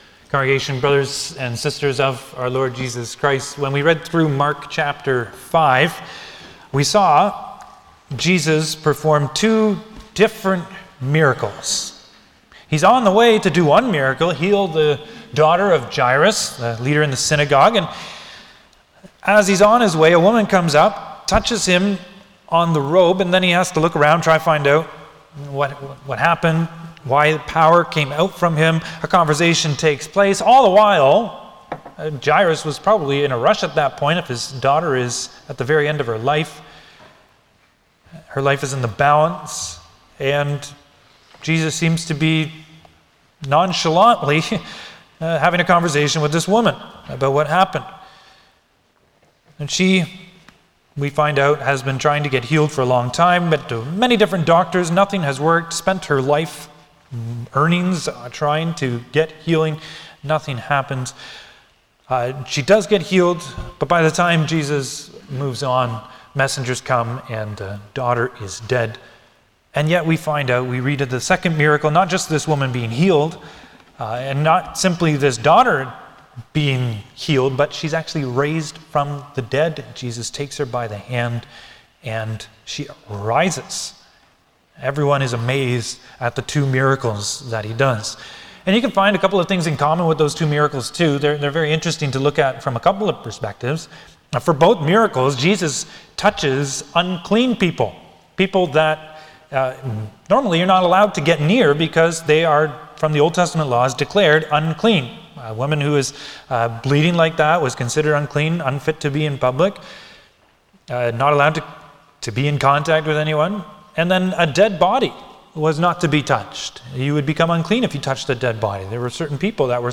Passage: Lord’s Day 7 Service Type: Sunday afternoon
07-Sermon.mp3